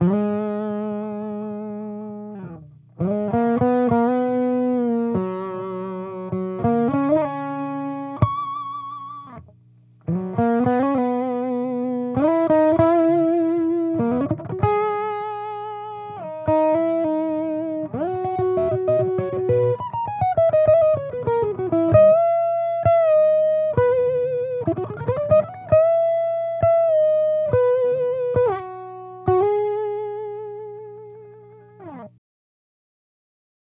Here are a few quick demos of my custom overdrive/distortion pedals for your listening pleasure.
Gold pedal 1N60 diodes with boost
As you can probably hear, the gold pedal has a lot less gain, even with a boost, so it’s better equipped for blues stuff than rock/metal solos.
I used a Sennheiser MD441-U microphone on-axis, edge of speaker cap, and about an inch away from the grill.
gold-pedal-1n60-diodes-with-boost.wav